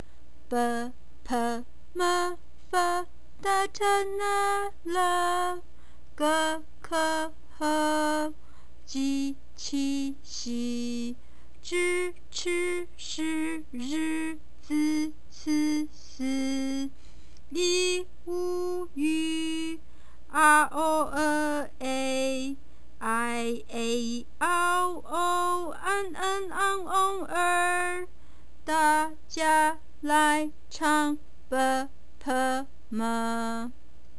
KA (the Chinese School of Greater KC - nonprofit org) Lesson One: Good Morning Miscellaneous 1 bpm pronunciation symbols bpm Twinkle Twinkle Little Star song Phrases Symbol Combinations last updated 04/27/03